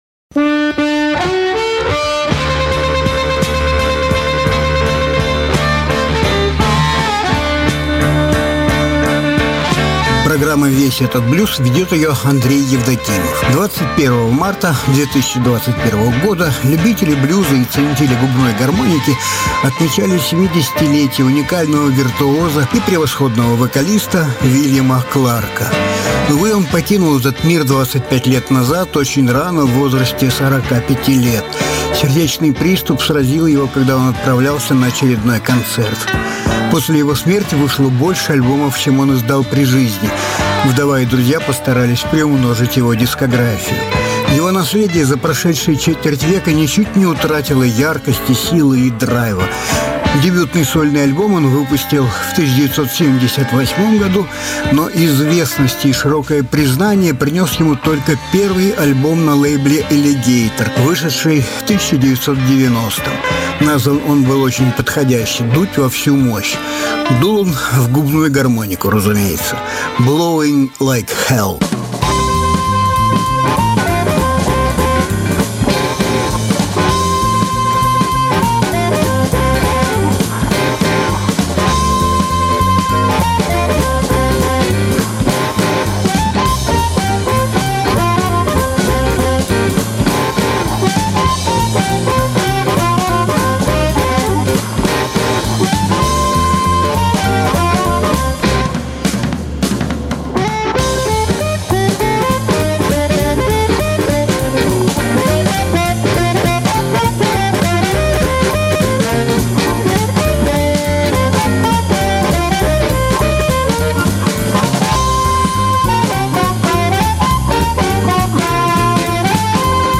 певец и мастер игры на губной гармонике
Жанр: Блюзы и блюзики